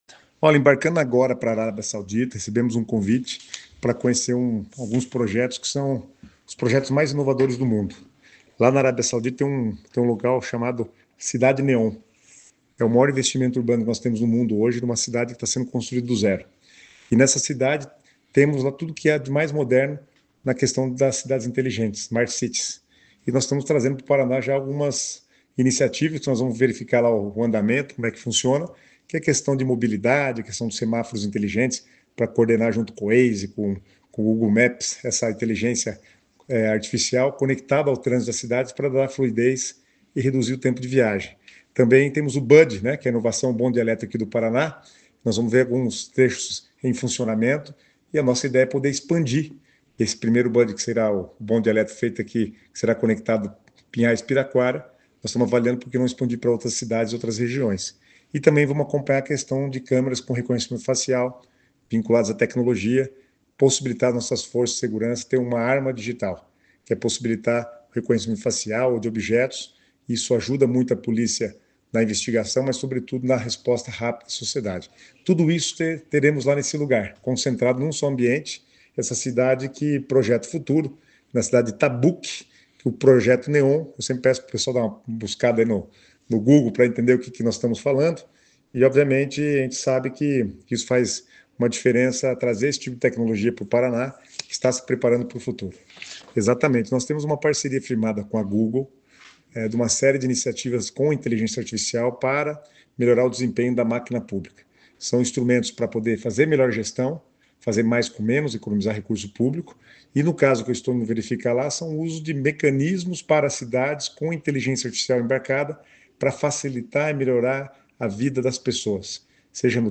Sonora do secretário das Cidades, Guto Silva, sobre a visita à Arábia Saudita